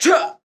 attack2.wav